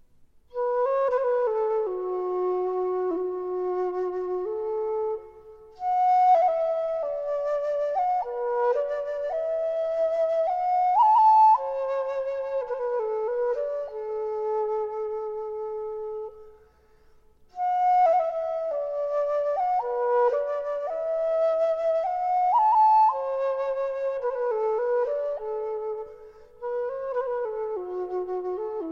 Category: Chinese Ringtones Mp3